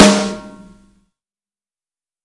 泛音小号
描述：我的网罗，其他样本和我打一个煎锅混合（真的） 一定声音
标签： 谐波 小鼓
声道立体声